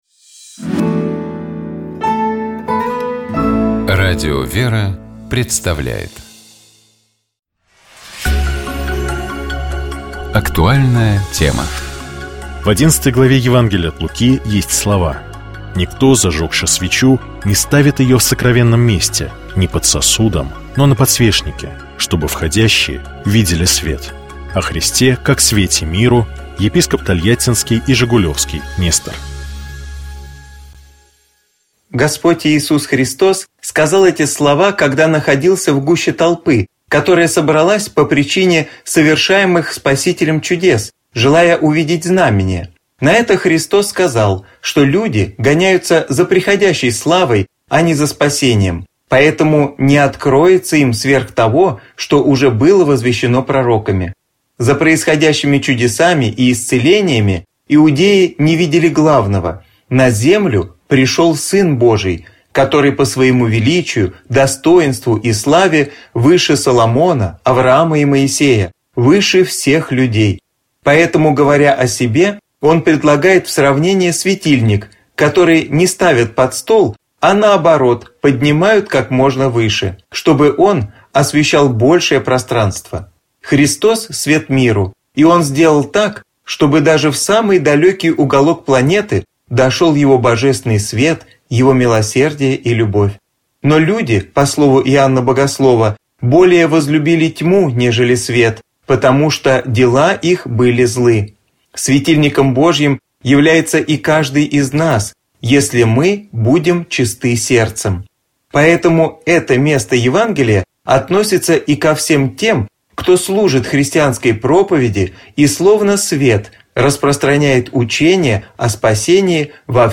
Известные актёры, режиссёры, спортсмены, писатели читают литературные миниатюры из прозы классиков и современников. Звучат произведения, связанные с утренней жизнью человека.